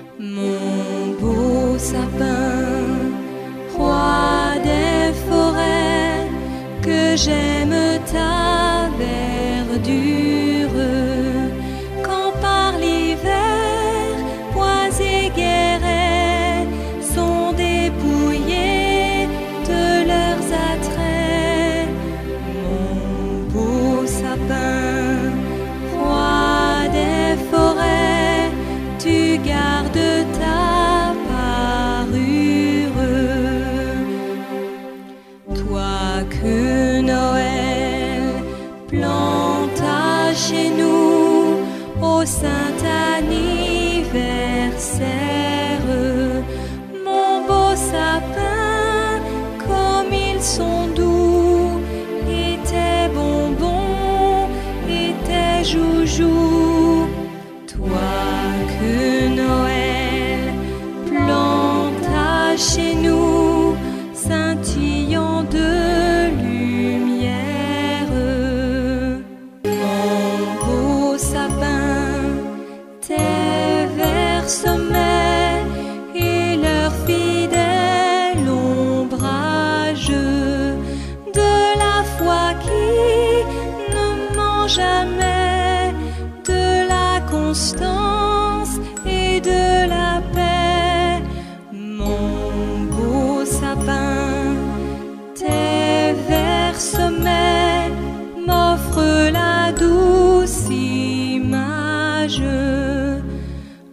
Übungsmusik aus einigen YouTube Videos herausgeschnitten (mittlere Qualität) - als mp3- oder wav-File